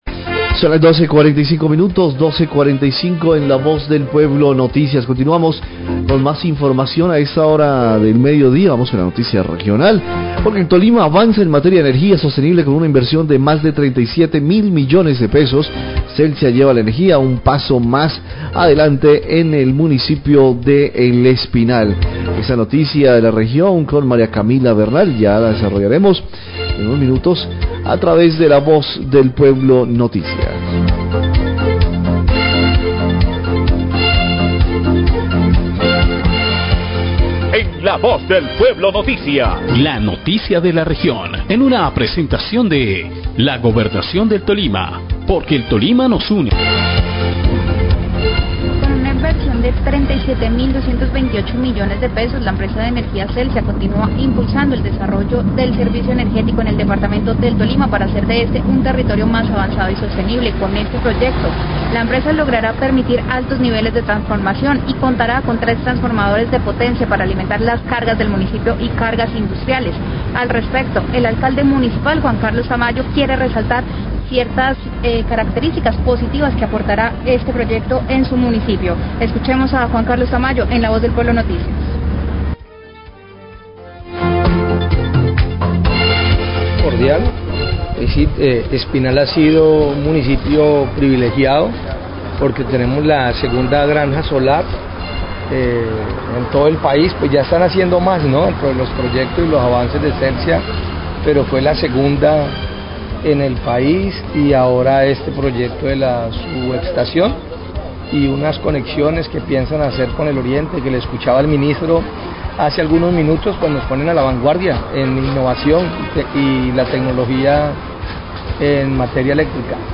En medio de la visita a la granja solar y la subestación eléctrica de Celsia en El Espinal, el alcalde de El Espinal, Juan Carlos Tamayo, destaca los beneficios para el municipío de estos dos proyectos energéticos.